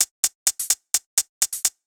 Index of /musicradar/ultimate-hihat-samples/128bpm
UHH_ElectroHatA_128-04.wav